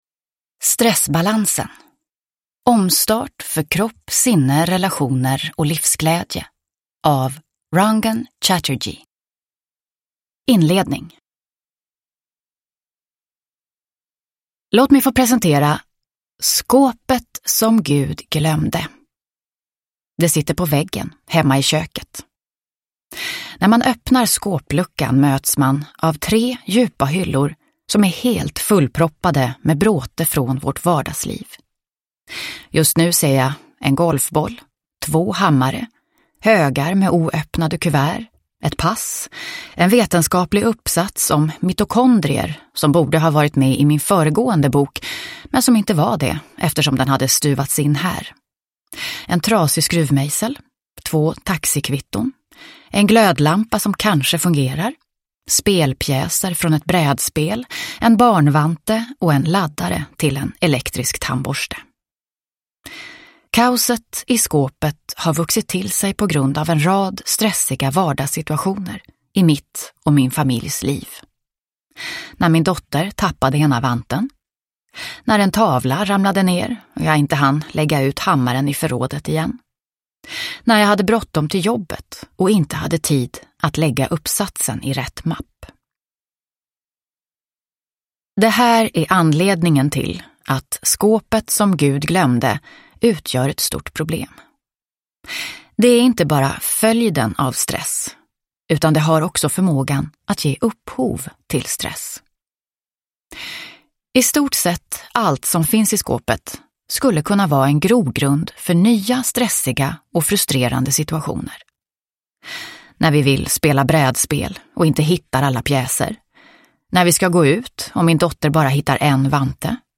Stressbalansen : omstart för kropp, sinne, relationer & livsglädje – Ljudbok – Laddas ner